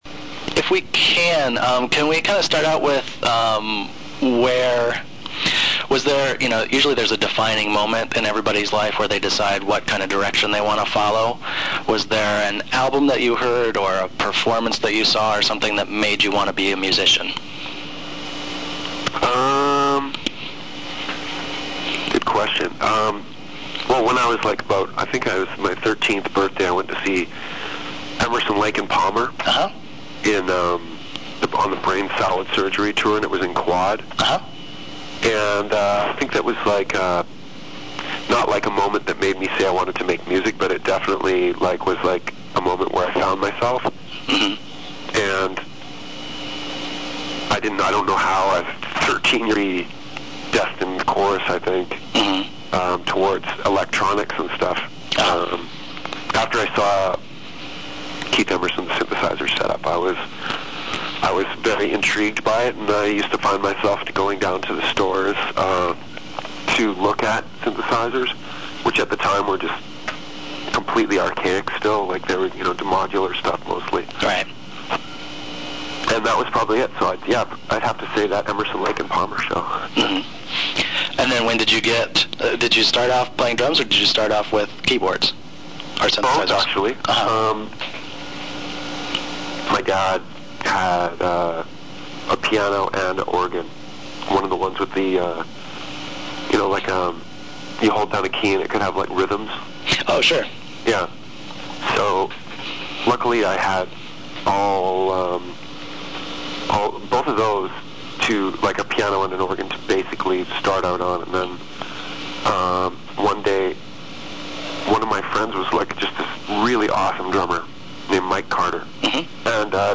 LAUNCH CEVIN KEY INTERVIEW (MP3 FILE)(NOTE: File is large and may take awhile to fully load.)